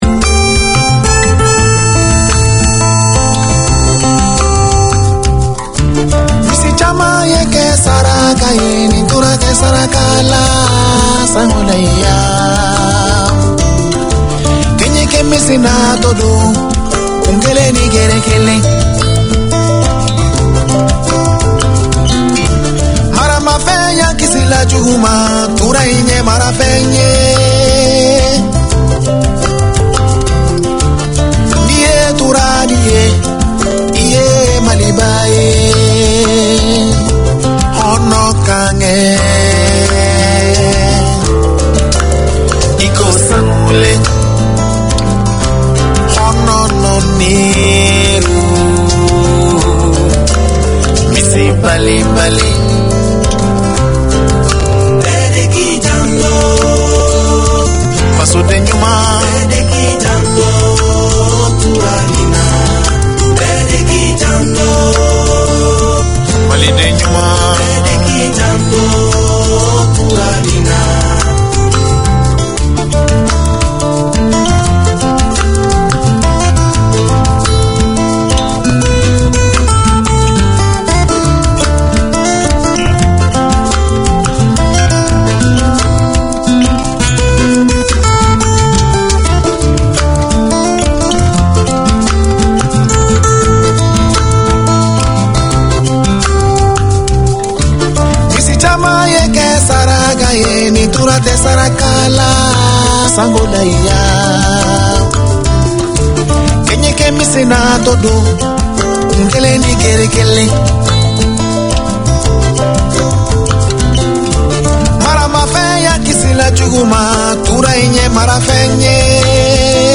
Radio made by over 100 Aucklanders addressing the diverse cultures and interests in 35 languages.
The Kahoa Tauleva Trust seeks to support the well-being of the Tongan community with information on employment, money management, wealth creation, housing, physical and spiritual health. You’ll hear interviews with experts and discussions on current and topical issues and get a chance to talkback with the hosts who’ll also share devotional time with listeners.